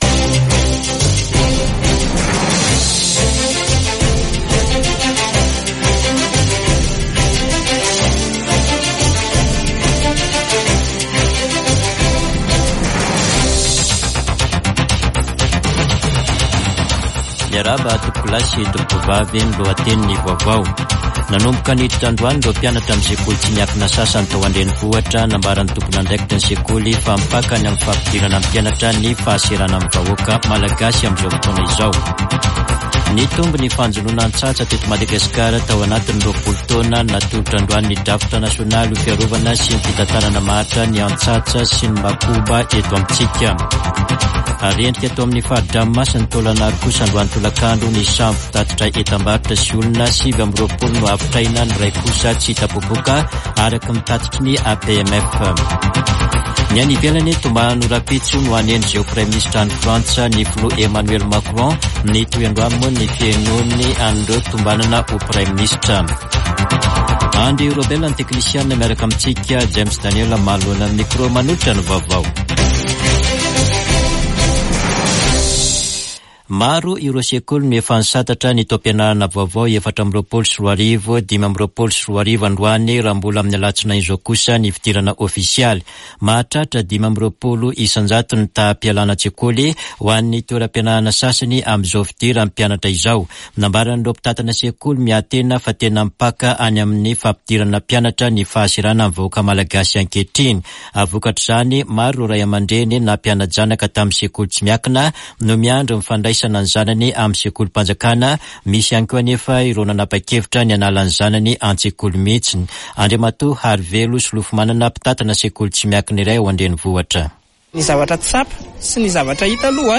[Vaovao hariva] Alatsinainy 2 septambra 2024